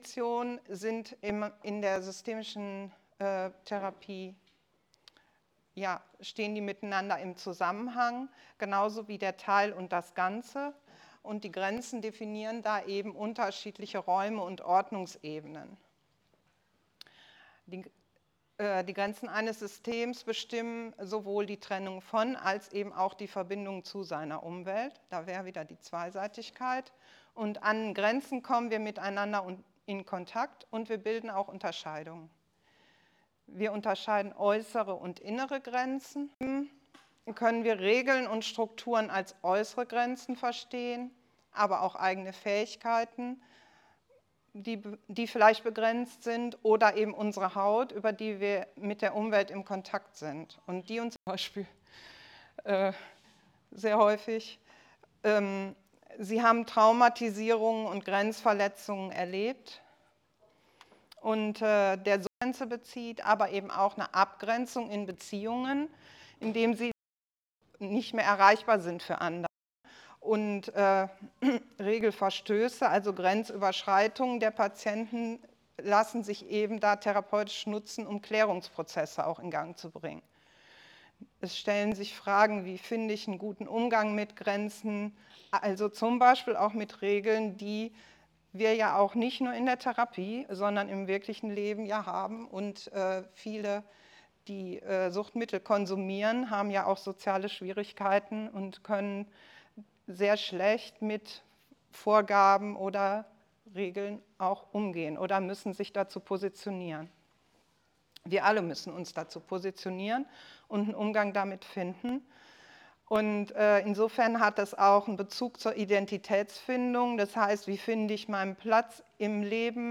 Vortrag 25.05.2024, Siegburg, 29. Rheinische Allgemeine PSYCHOtherapietagung: Die Verantwortung der Suggestion - Inszenierte Zuversicht